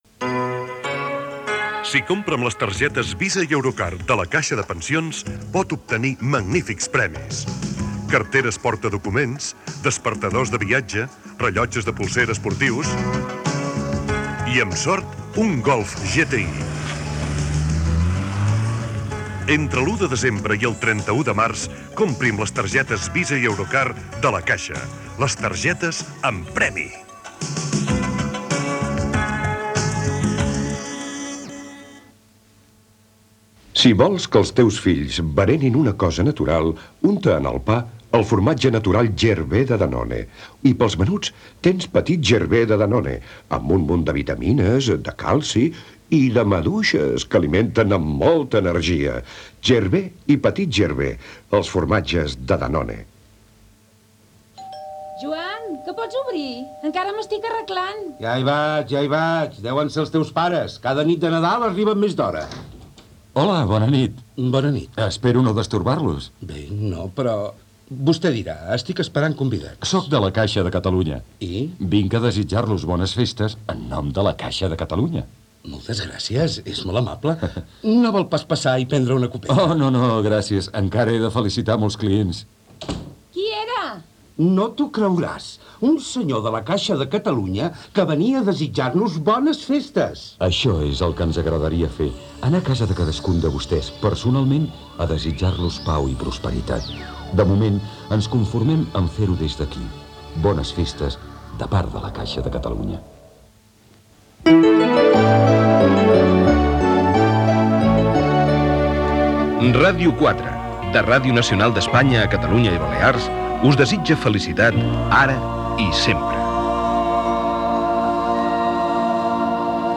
Gènere radiofònic Publicitat Anunciant La Caixa de Pensions, Gervais de Danone, Caixa de Catalunya Data emissió 1989-12-25 Banda FM Localitat Barcelona Durada enregistrament 02:01 Idioma Català Any 1989